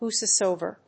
アクセント・音節whòse・soéver
whosesoever.mp3